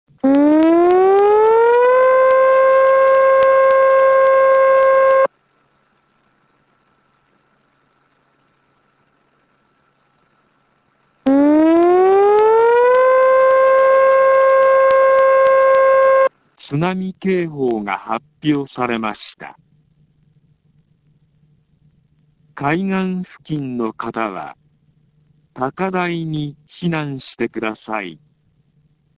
以下の情報を受信した場合、市内にある防災行政無線から、瞬時に3回繰り返し放送されます。
津波警報
放送内容は、サイレン5秒吹鳴、6秒休止を2回繰り返した後「津波警報が発表されました。海岸付近の方は高台に避難して下さい。」
tunami.mp3